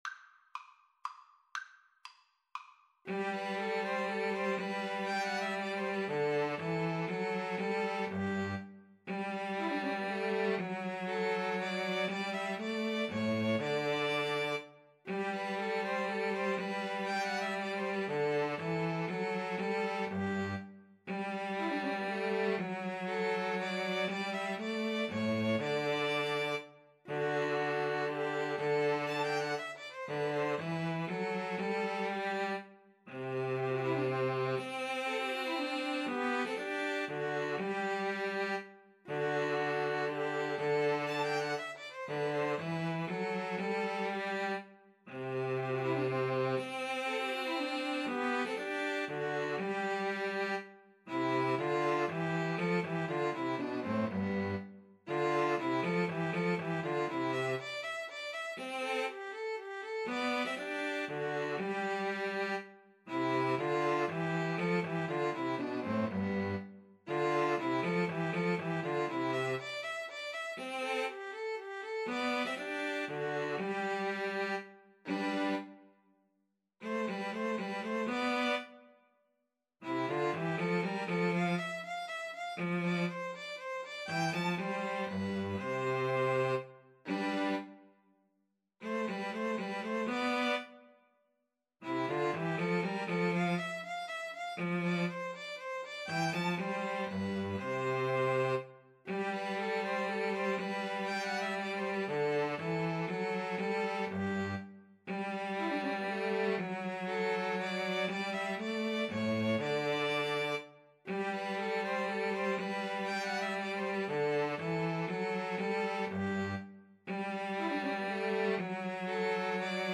G major (Sounding Pitch) (View more G major Music for 2-Violins-Cello )
3/4 (View more 3/4 Music)
2-Violins-Cello  (View more Intermediate 2-Violins-Cello Music)
Classical (View more Classical 2-Violins-Cello Music)